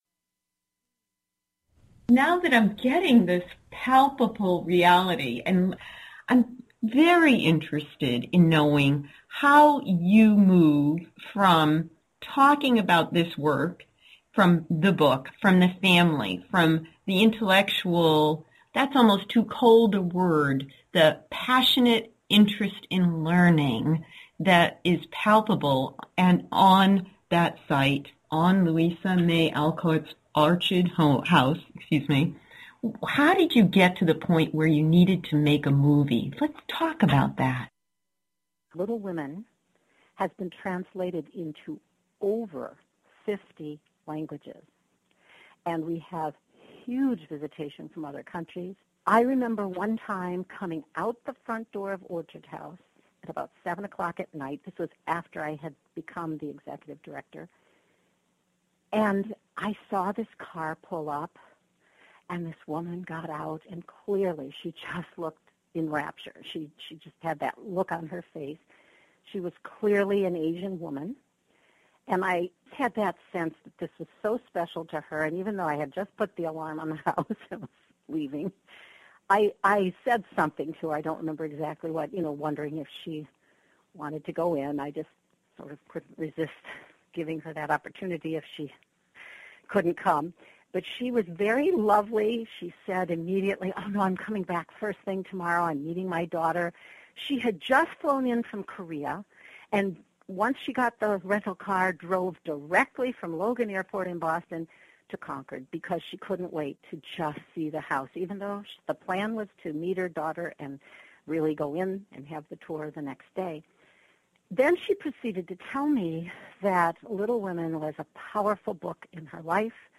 Part three of 4-part interview
I wish to thank WCOM-FM for granting permission to rebroadcast this interview.